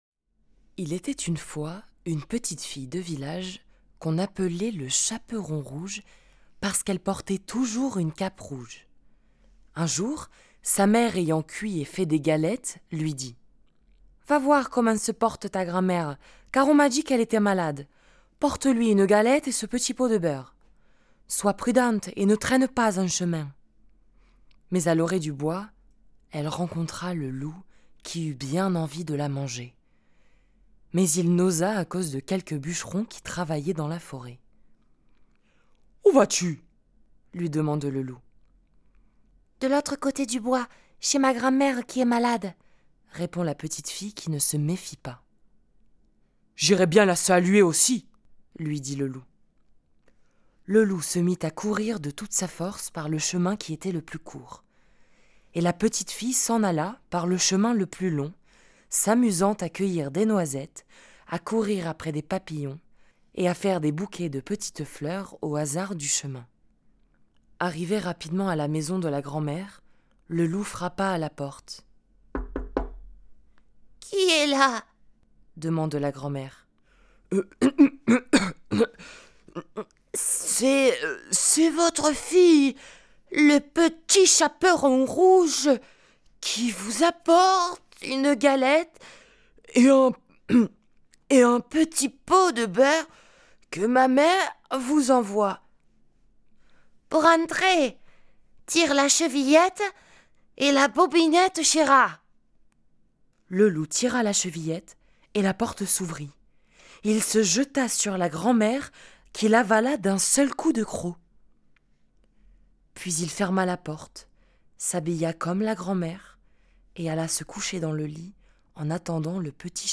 Voix off
16 - 40 ans - Mezzo-soprano